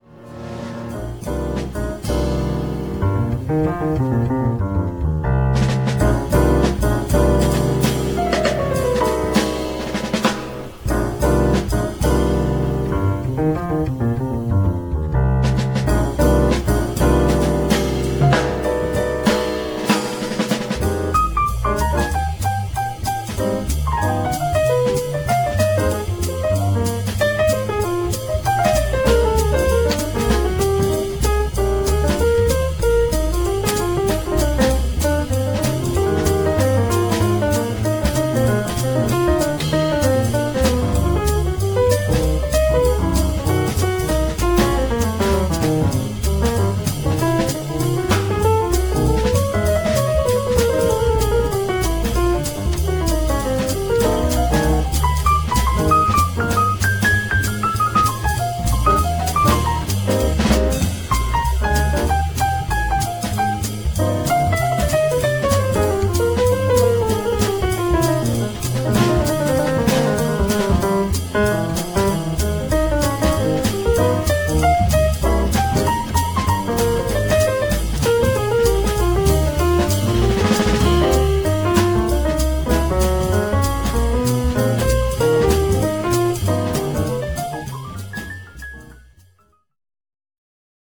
piano
alto & tenor saxophones; flute
trombone
bass
drums
percussion